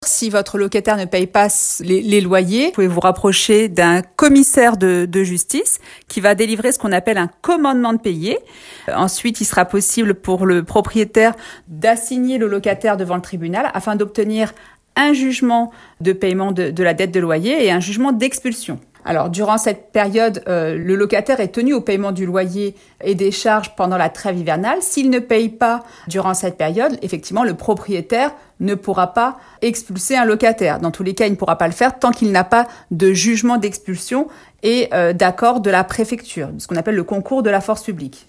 juriste spécialiste en matière de logement, était à notre antenne.